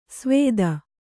♪ svēda